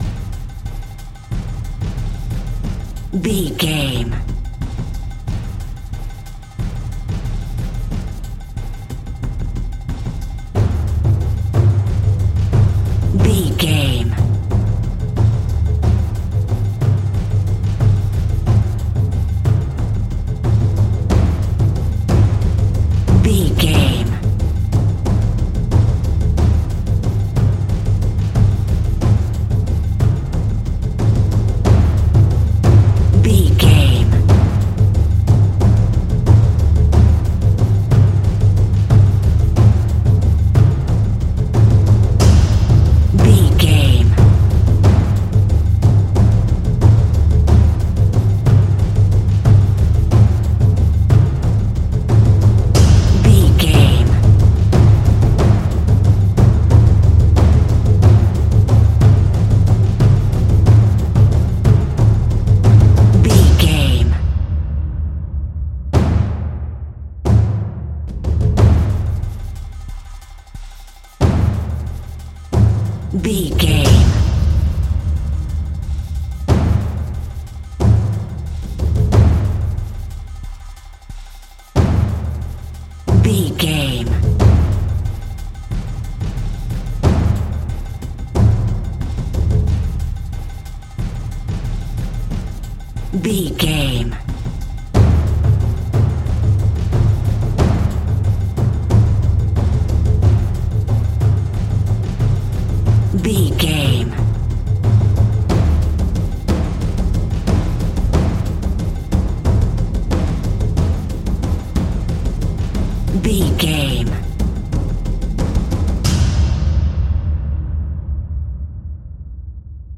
Exotic and world music!
Atonal
world beat
percussion
cymbals
gongs
taiko drums
timpani